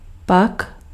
Ääntäminen
UK : IPA : /ˈðɛn/ US : IPA : /ˈðɛn/ Australia: IPA : /ðen/